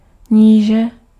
Ääntäminen
France: IPA: [de.pʁɛ.sjɔ̃]